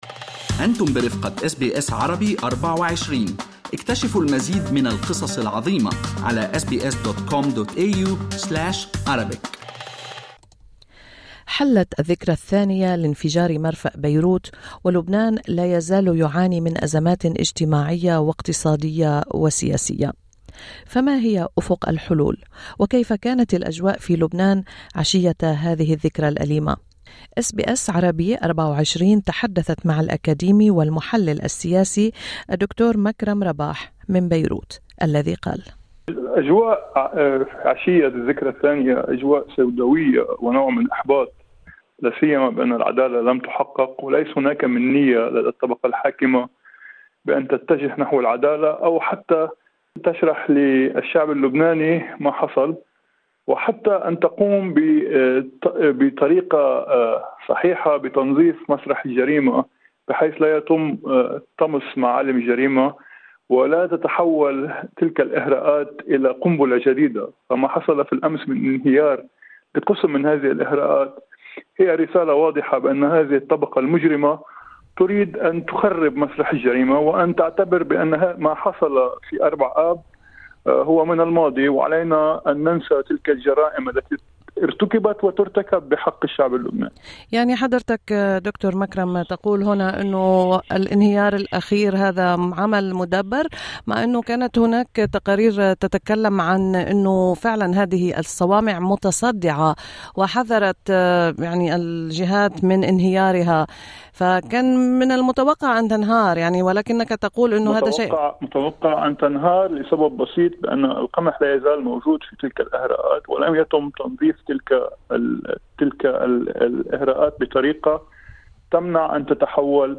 يتحدث إلى SBS عن الأوضاع اللبنانية في الذكرى الثانية لانفجار مرفأ بيروت